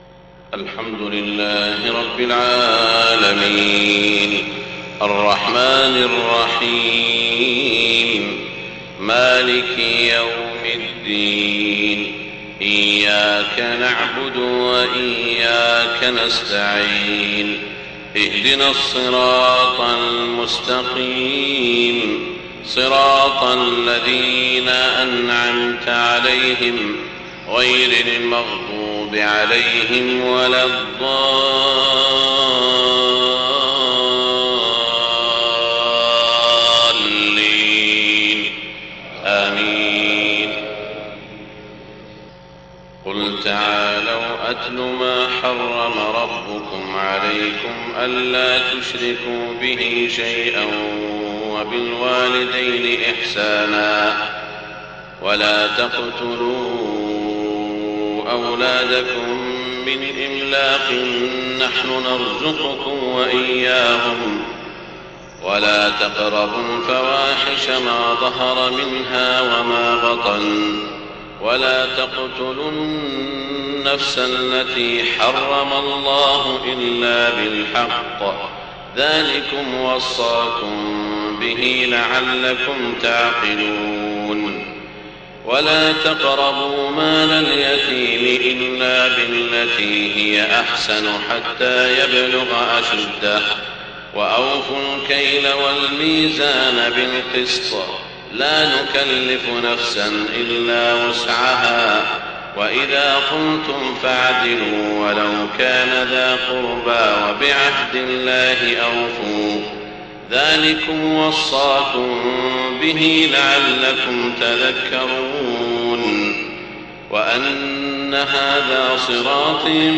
صلاة الفجر 22 صفر 1430هـ خواتيم سورة الأنعام 151-165 > 1430 🕋 > الفروض - تلاوات الحرمين